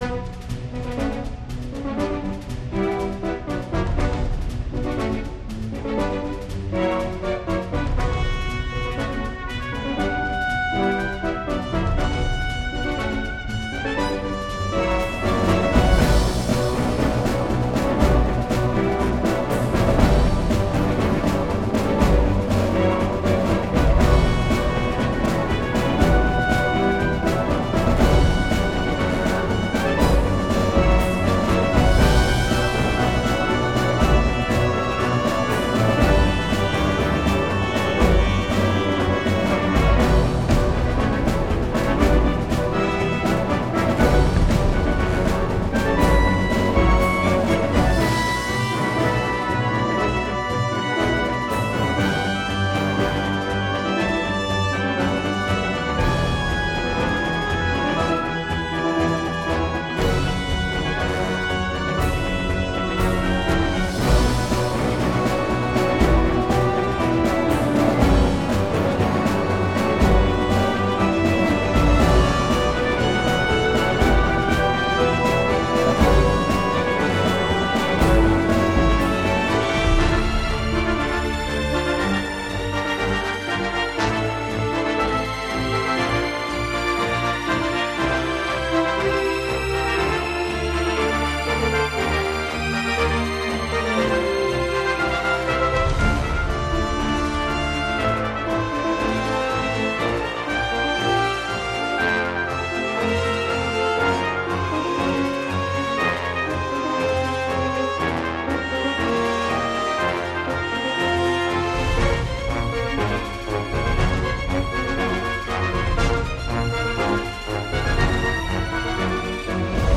Military Music